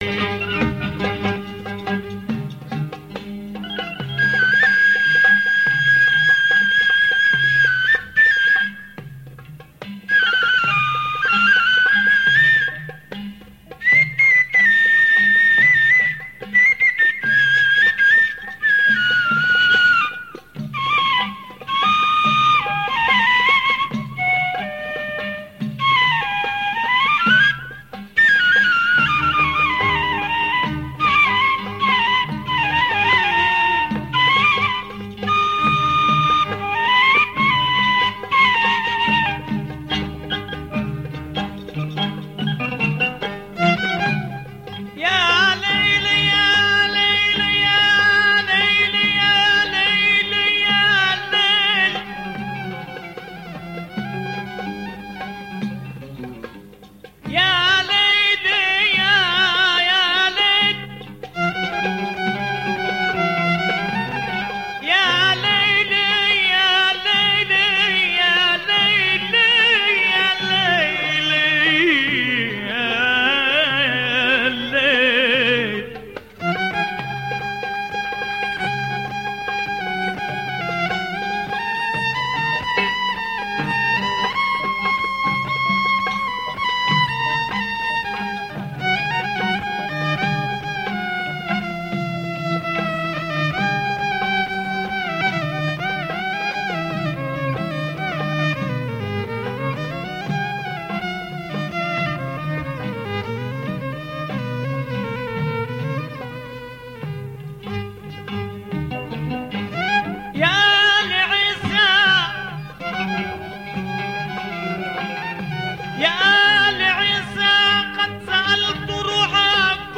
Amazing album of bedouin music / music from the desert.